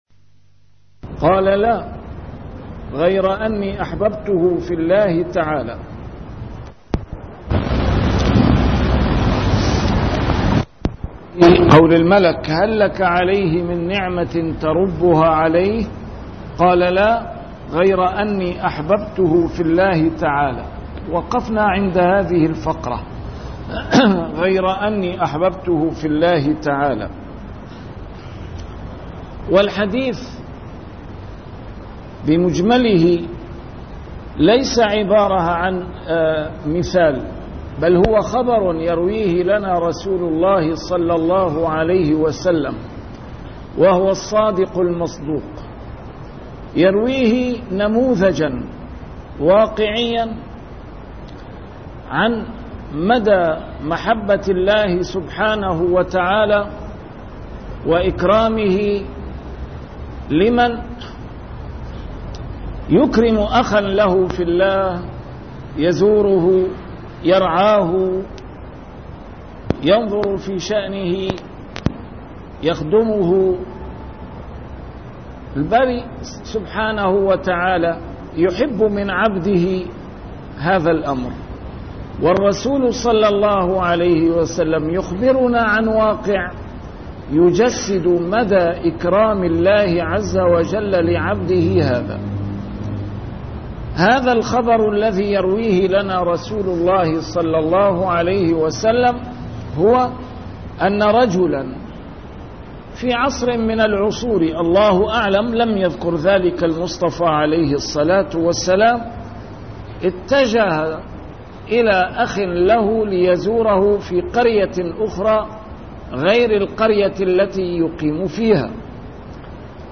A MARTYR SCHOLAR: IMAM MUHAMMAD SAEED RAMADAN AL-BOUTI - الدروس العلمية - شرح كتاب رياض الصالحين - 425- شرح رياض الصالحين: زيارة أهل الخير